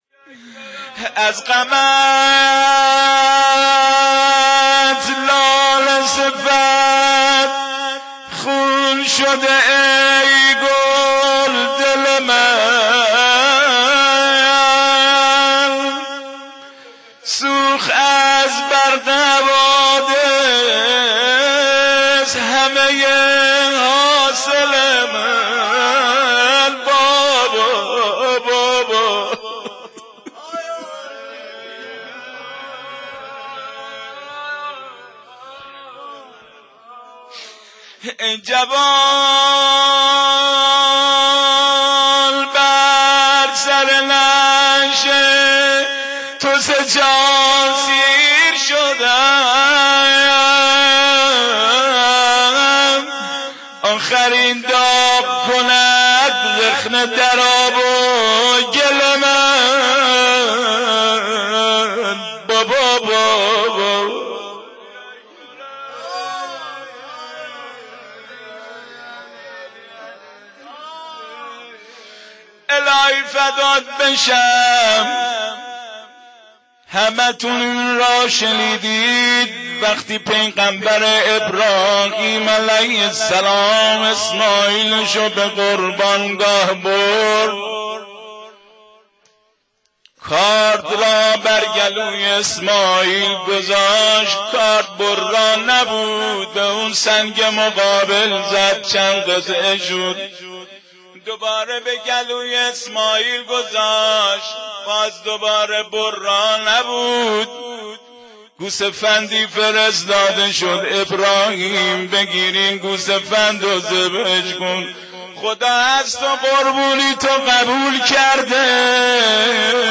روضه حضرت علی اکبر 1384
roze-ali-akbar-2-1384.wma